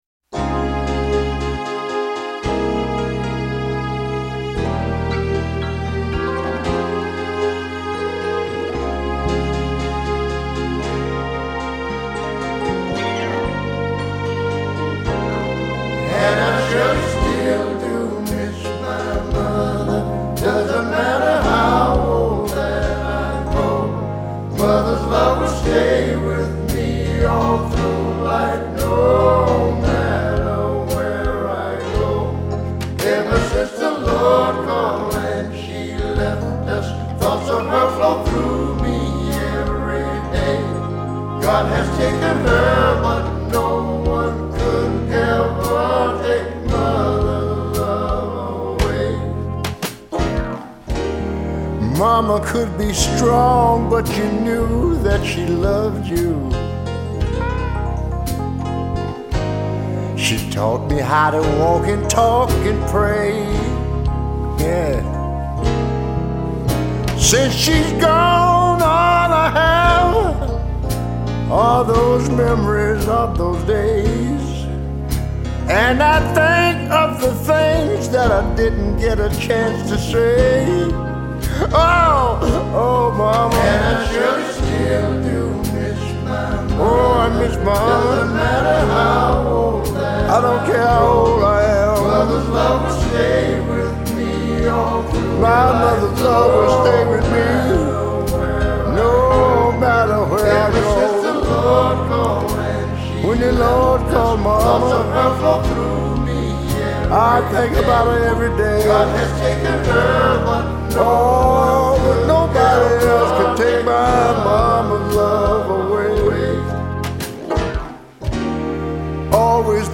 Soul музыка